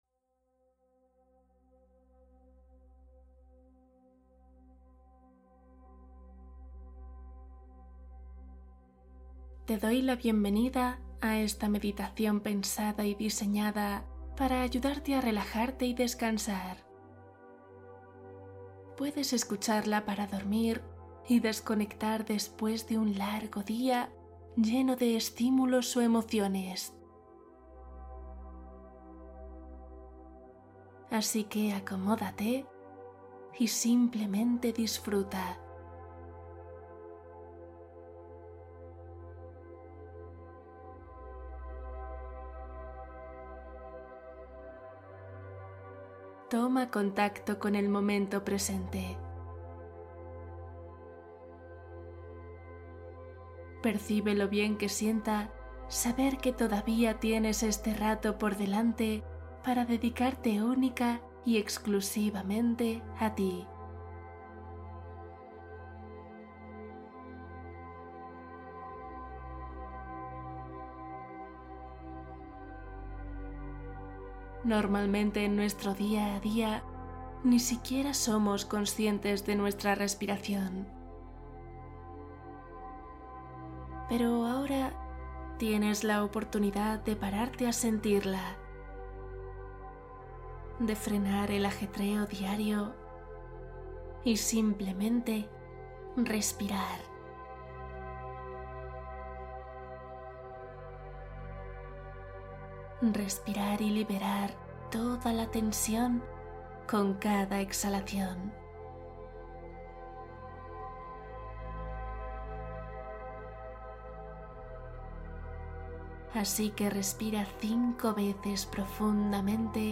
Descanso zen: una práctica guiada para dormir con mayor profundidad